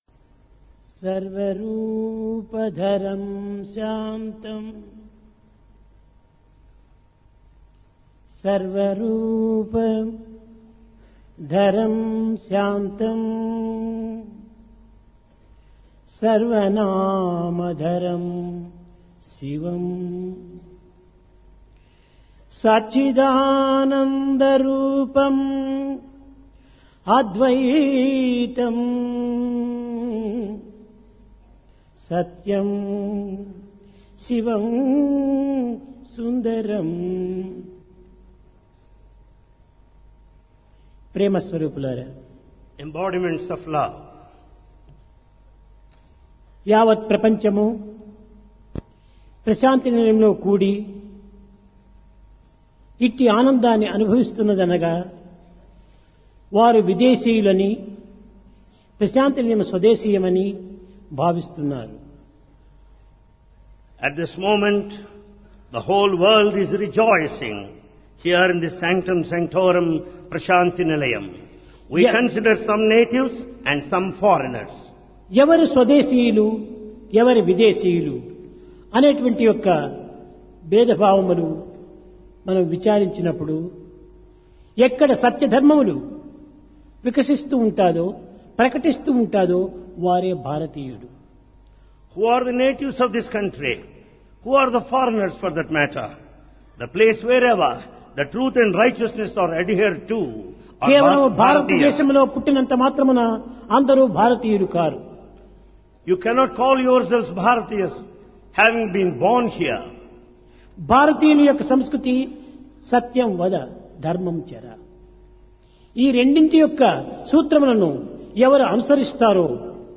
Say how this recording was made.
Place Prasanthi Nilayam